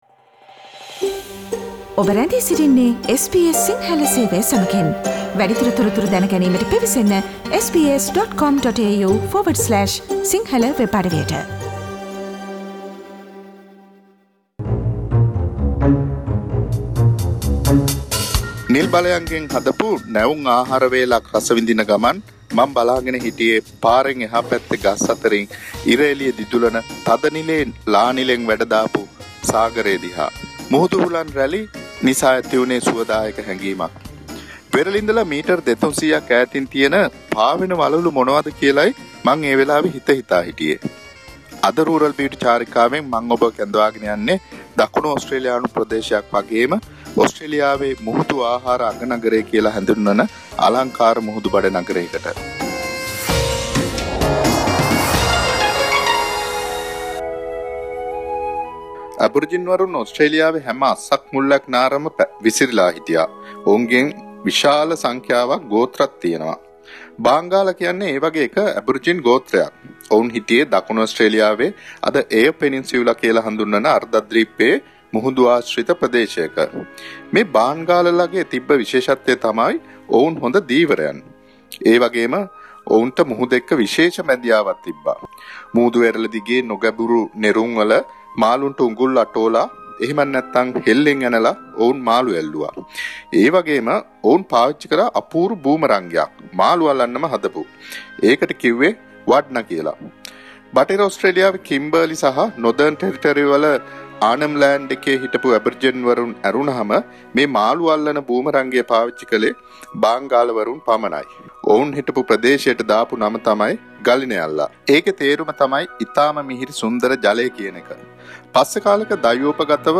Radio journey to the Sea Food Capital in Australia: Port Lincoln